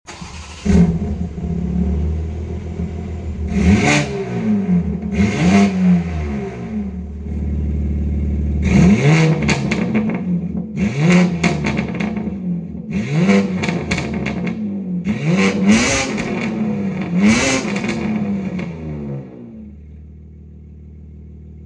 Listen to this brute!
• M Sports Exhaust